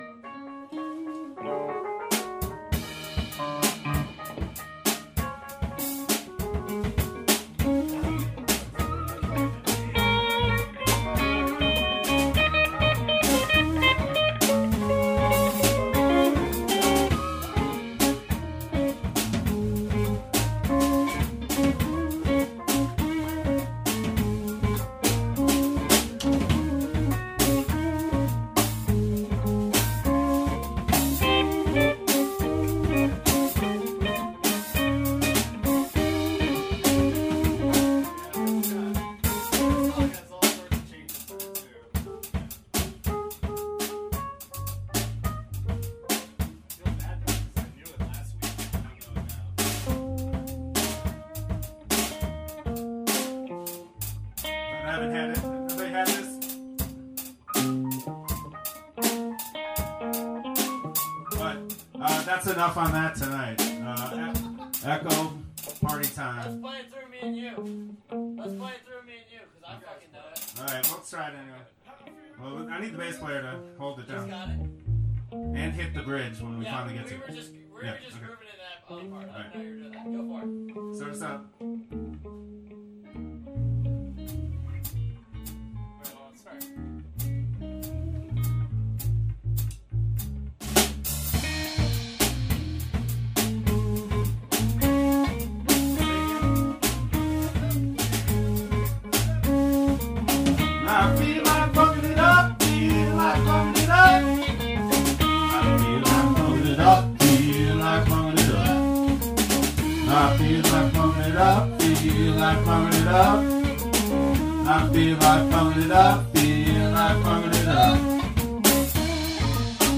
Rehearsal